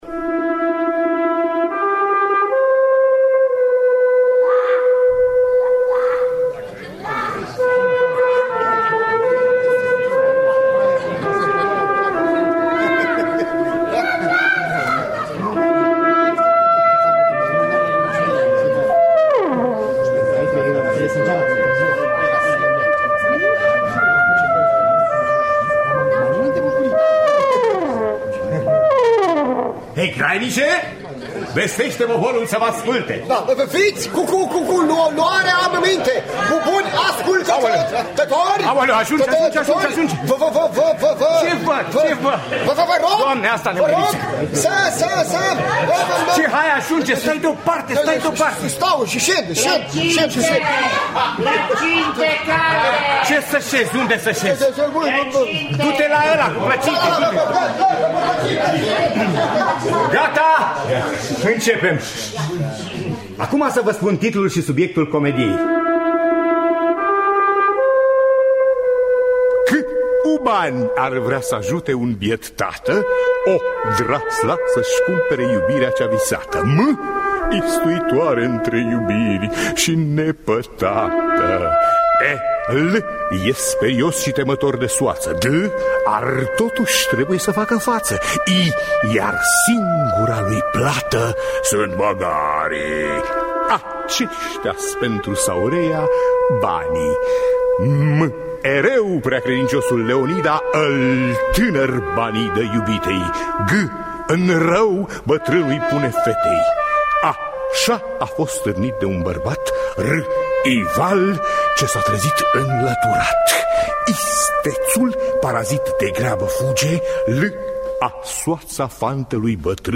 Comedia măgarilor de Plaut – Teatru Radiofonic Online
Interpretează: Radio Brass Quintet